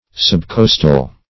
Subcostal \Sub*cos"tal\, a. (Anat. & Zool.)